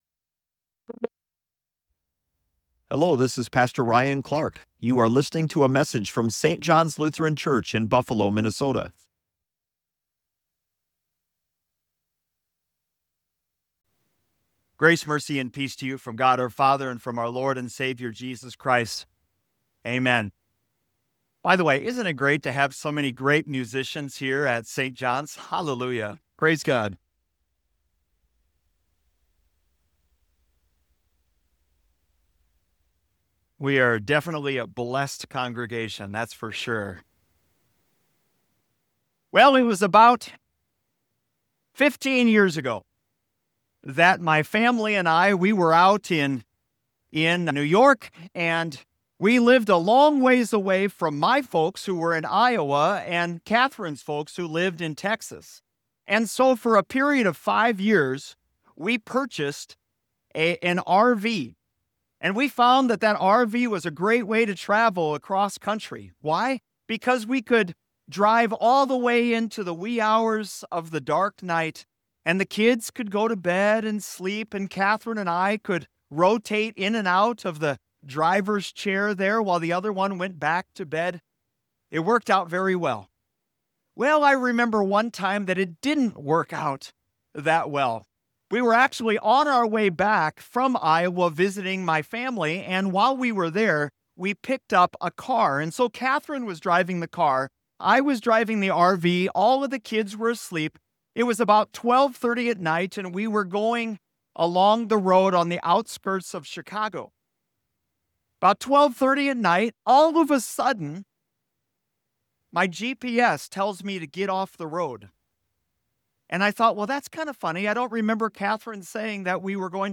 🌴 We all need the power that leads to wise choices! 💪 Find out more in this Palm Sunday message.